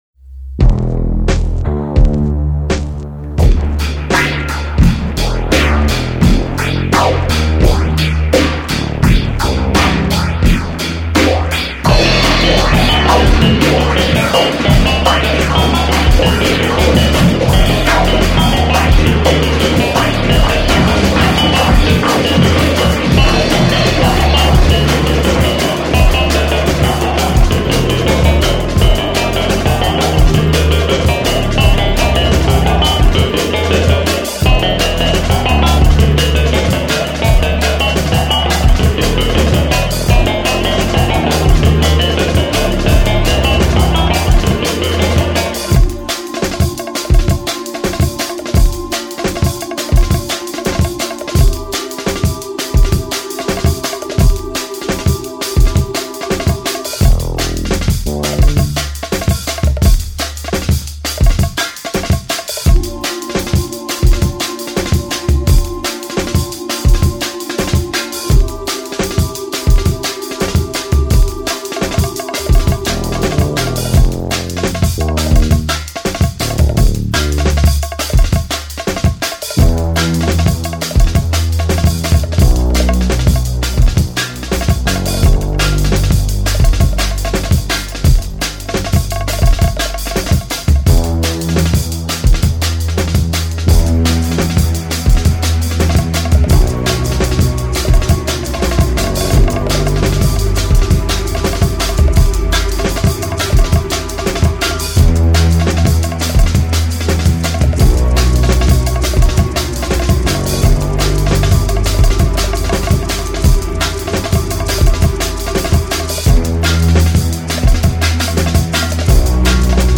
Música
ritmos sincopados, su tech-step cardiaco
ritmos quebrados y doblados.